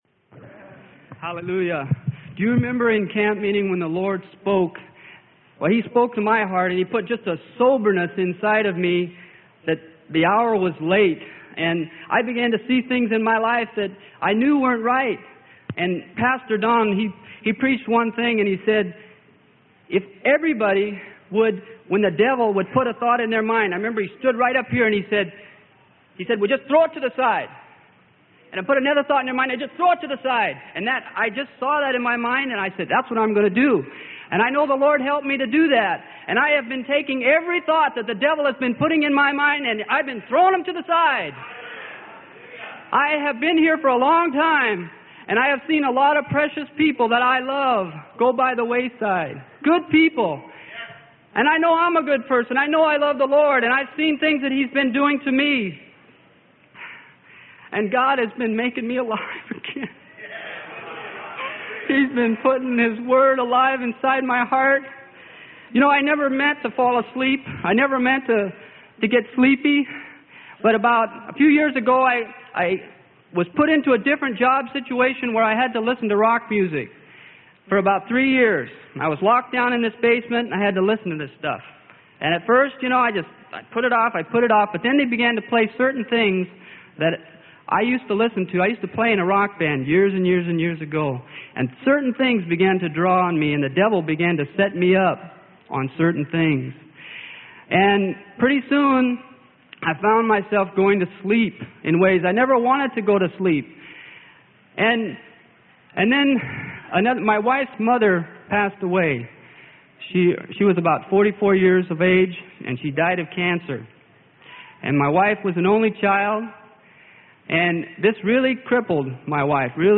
Sermon: Testimonies of Death to Life - Spiritually - Freely Given Online Library